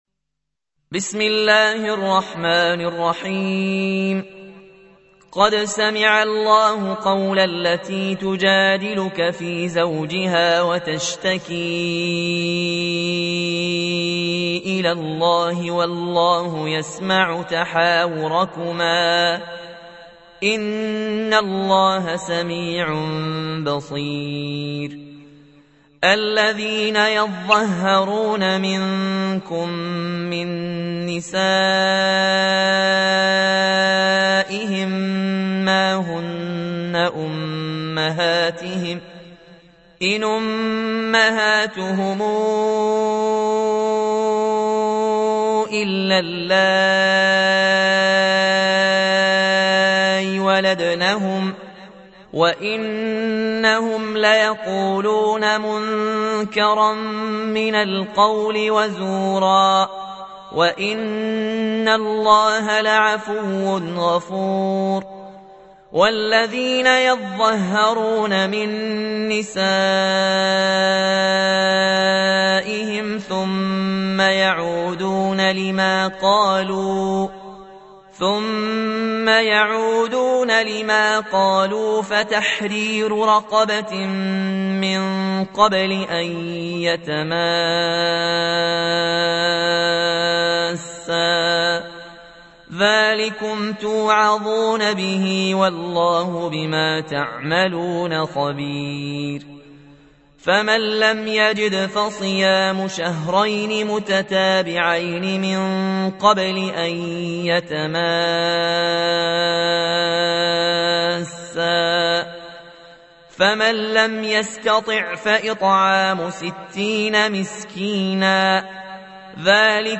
تحميل : 58. سورة المجادلة / القارئ ياسين الجزائري / القرآن الكريم / موقع يا حسين